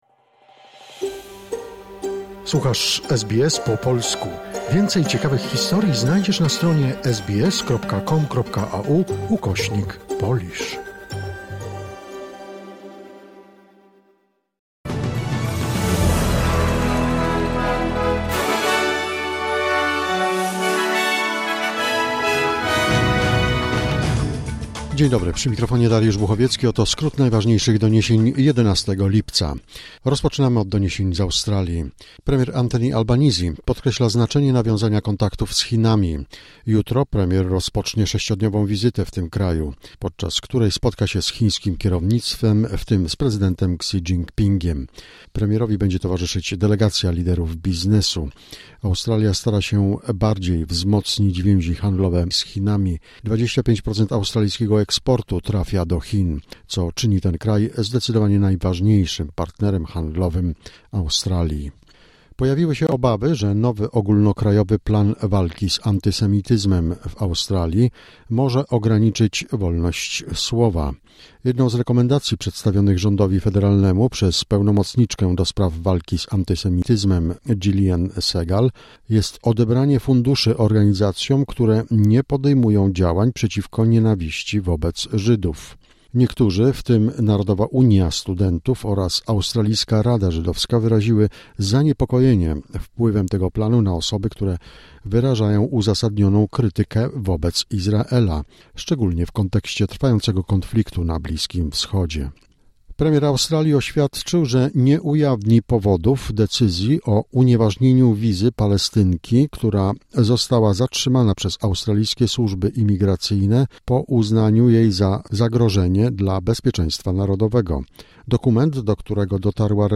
Wiadomości 11 lipca SBS News Flash